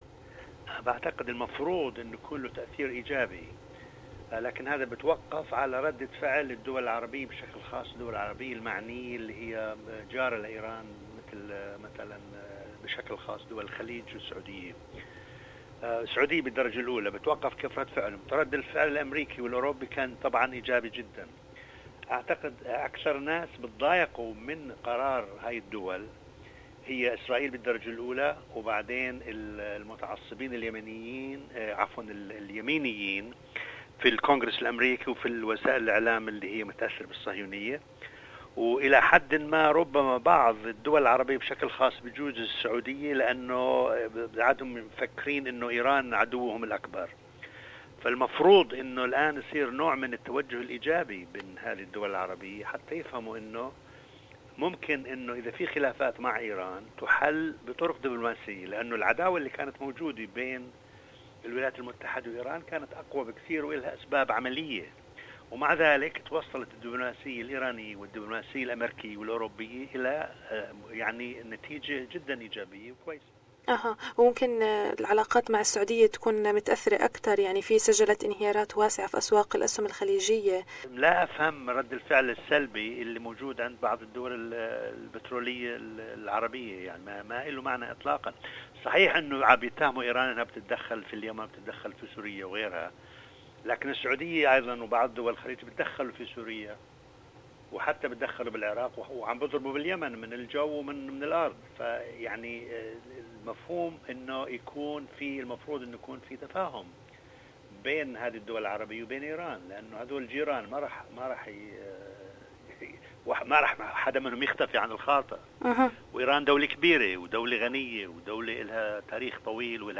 للمزيد في المقابلة التالية